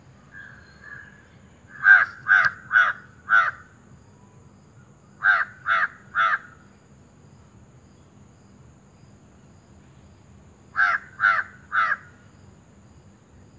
Large billed crow
Corvus macrorhynchos